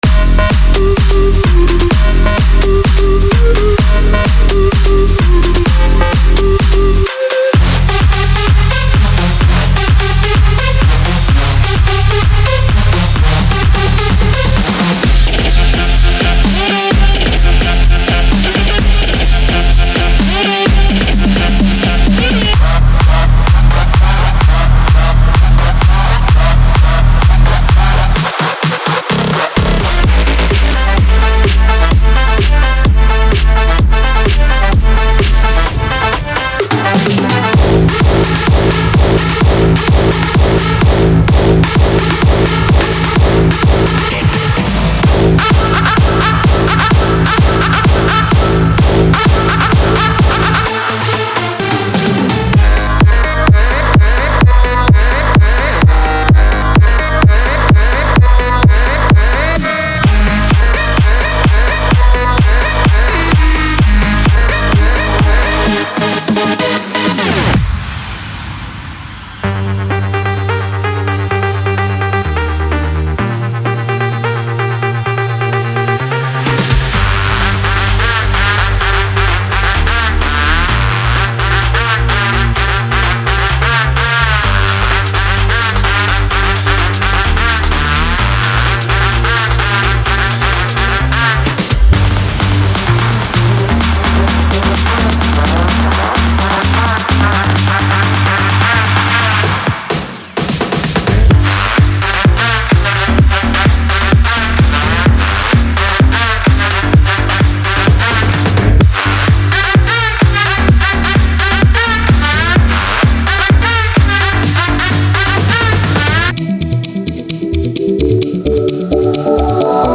整合了总共70G的包装非常适用于Hard，House，BigRoom等风格电子音乐制作